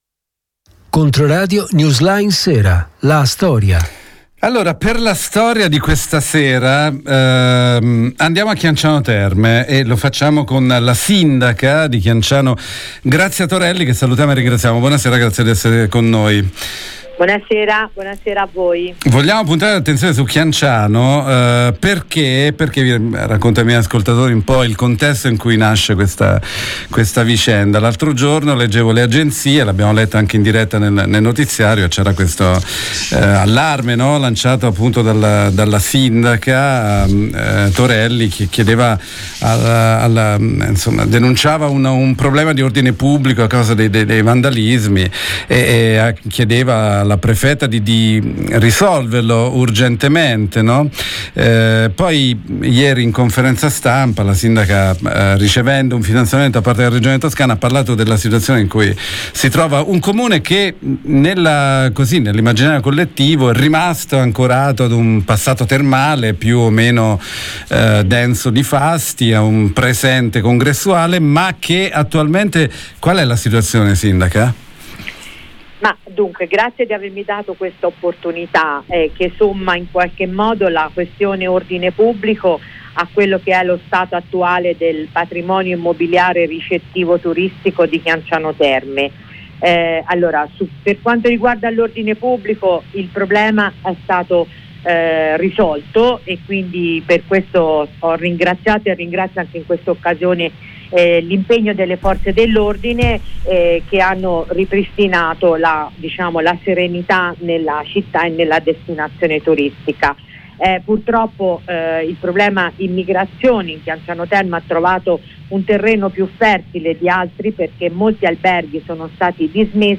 Intervista con la sindaca Grazia Torelli sul presente (difficile) e sul futuro (sperato) della cittadina termale